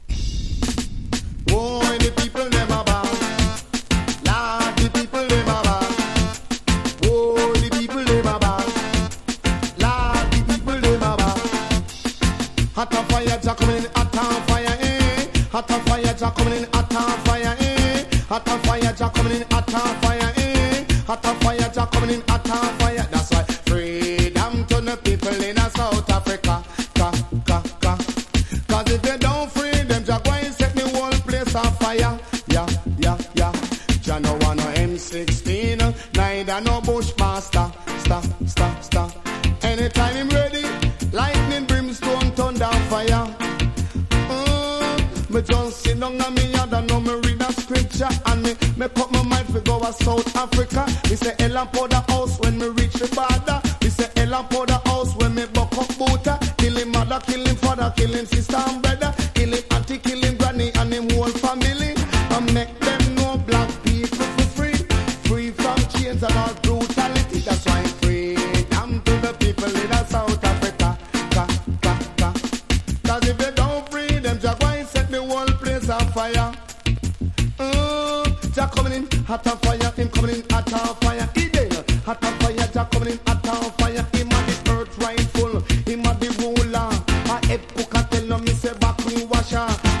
• REGGAE-SKA
小気味よいフロウが気持ち良い
DANCE HALL